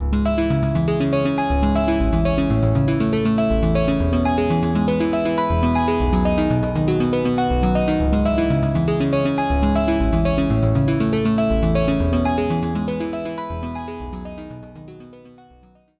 This is part of the FM Suite with vintage sounds based on iconic instruments: